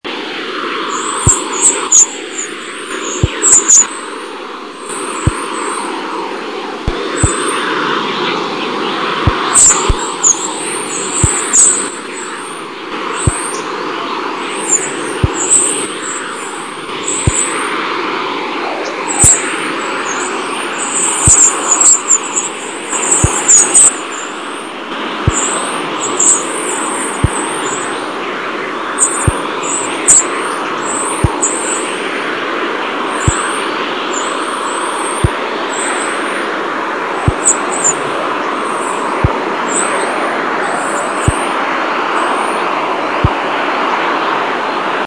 'Dawn chorus' signals detected by ESA Cluster’s WBD (Wide Band Data) instrument. High-energy electrons get trapped in the Earth’s radiation belts. When they are accelerated by the electromagnetic field, they produce this familiar sound.